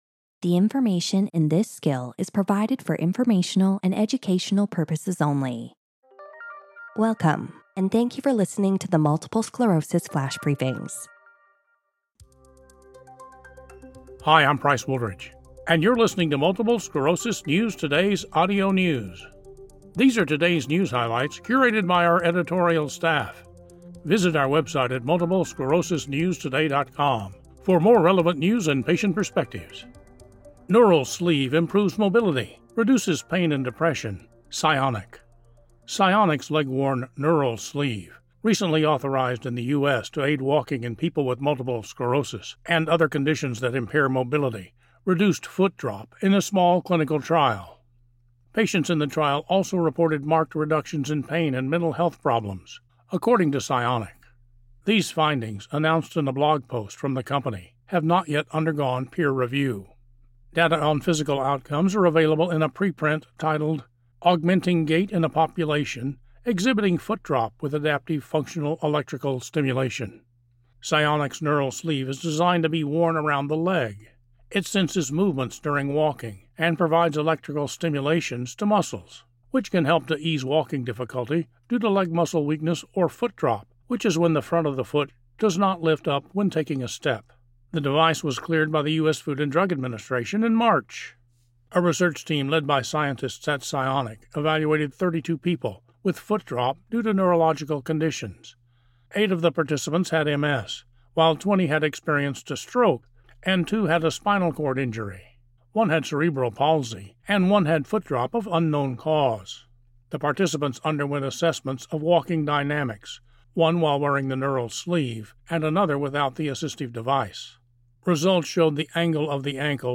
reads a news article about Cionic’s Neural Sleeve and how it reduced foot drop and eased other symptoms in a small clinical trial.